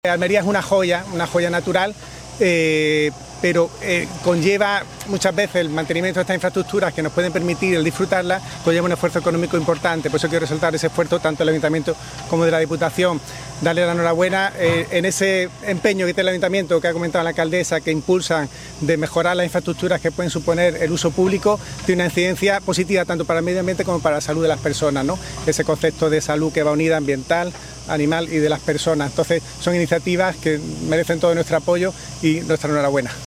MANUEL-DE-LA-TORRE-DELEGADO-SOSTENIBILIDAD-Y-MEDIOAMBIENTE.mp3